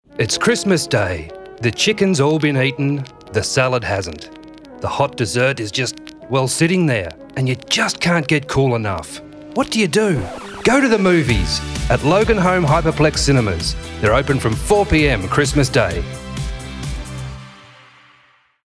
Male
My natural Australian accent is genuine and engaging.
Radio Commercials
Commercial Demo
0110Cinema_Christmas_advertisement.mp3